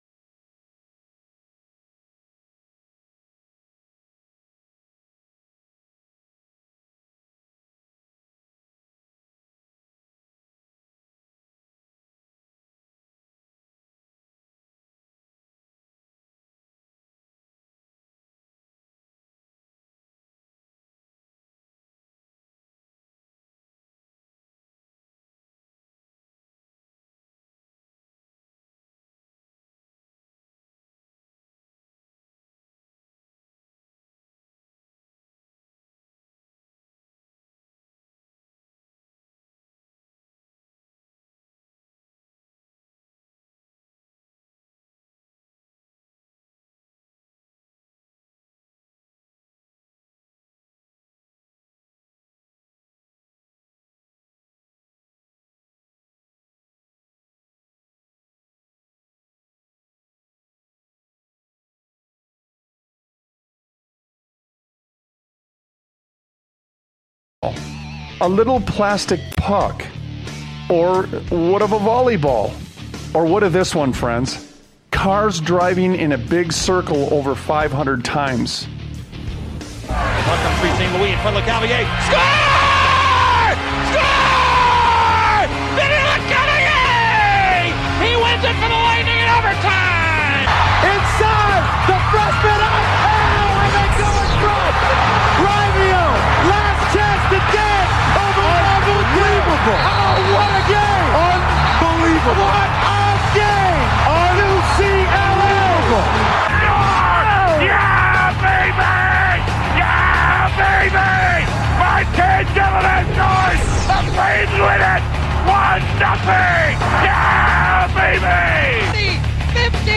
Talk Show Episode, Audio Podcast, Sons of Liberty Radio and What's Wrong With This One? on , show guests , about What's Wrong With This One, categorized as Education,History,Military,News,Politics & Government,Religion,Christianity,Society and Culture,Theory & Conspiracy